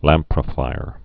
(lămprə-fīr)